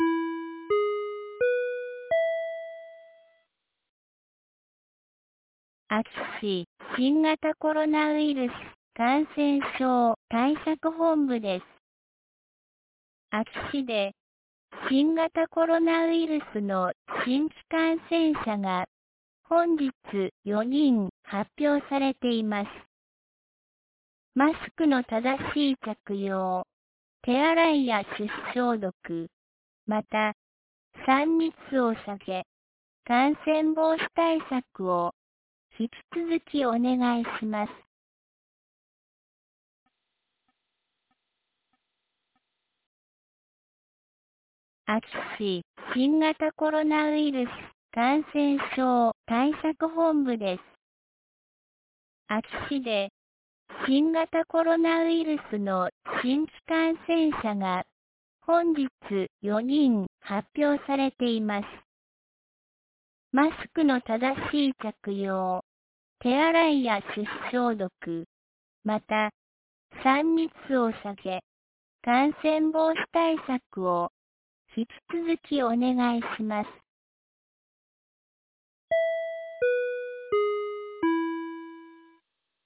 2022年09月07日 17時10分に、安芸市より全地区へ放送がありました。